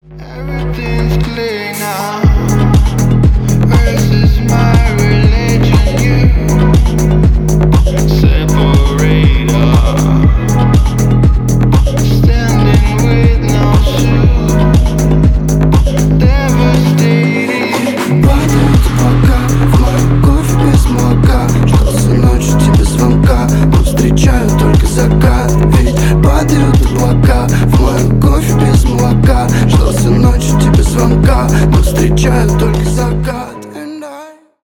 клубные , deep house , рэп